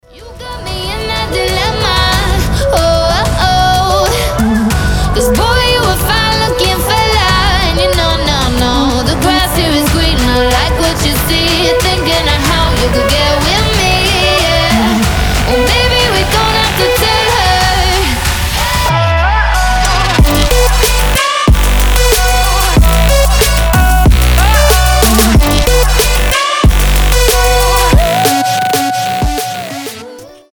• Качество: 320, Stereo
Electronic
future bass
Drumstep